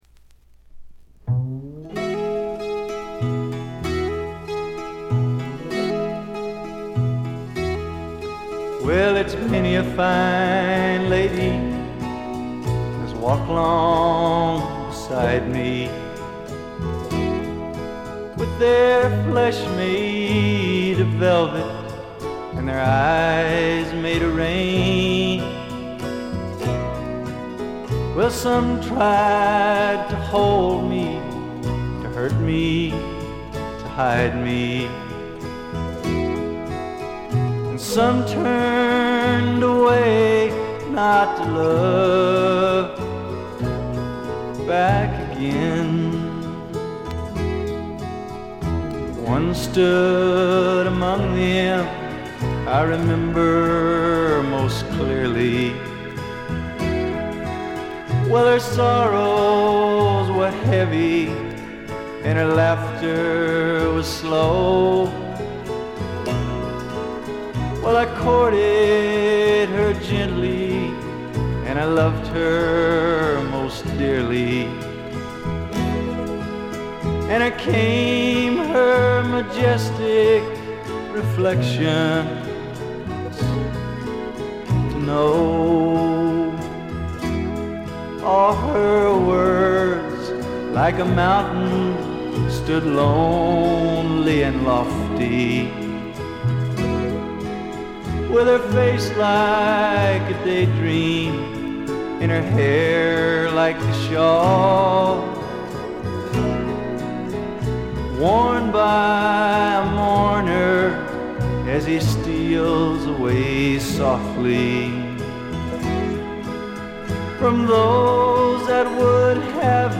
極めて良好に鑑賞できます。
試聴曲は現品からの取り込み音源です。